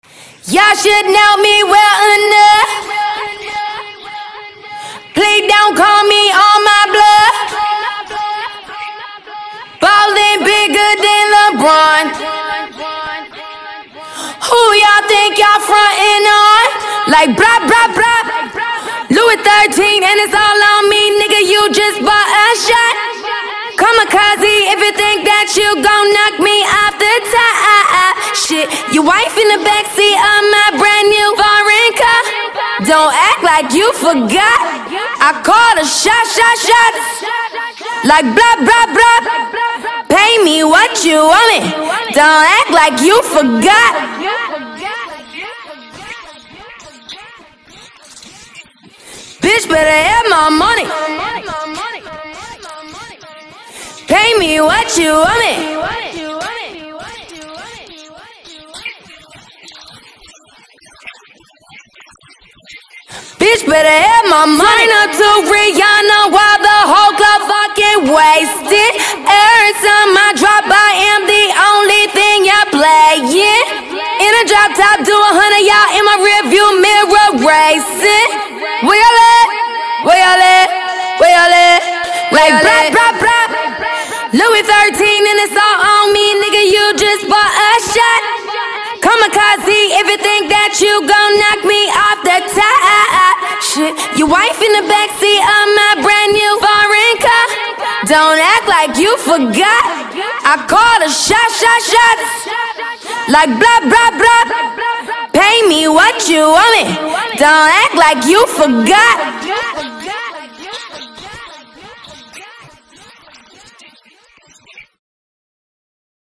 Скачать Зарубежные акапеллы [150]